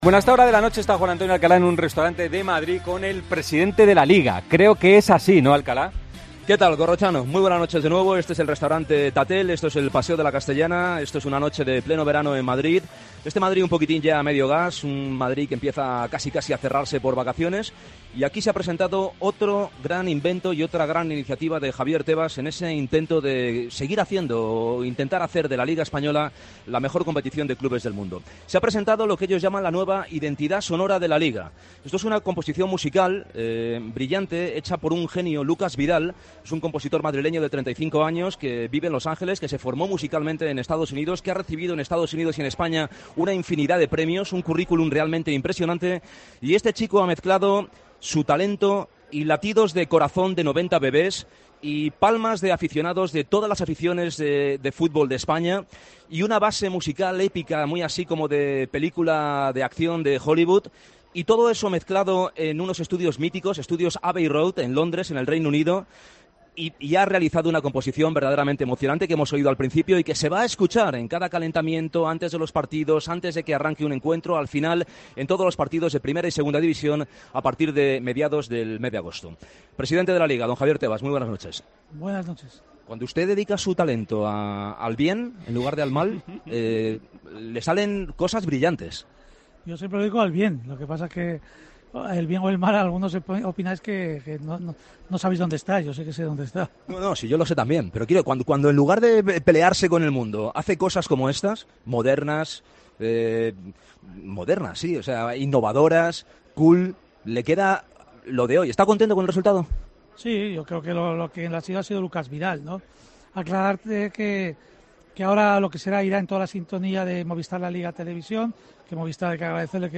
El presidente de LaLiga analizó en 'El Partidazo de COPE' el estreno del himno de LaLiga o el desenlace jurídico del debate de los horarios.